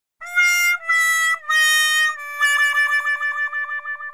Play Fail Sounds - SoundBoardGuy
Play, download and share Fail sounds original sound button!!!!
fail_5j8QJEa.mp3